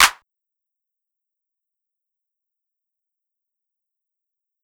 Clap (No Long Talk).wav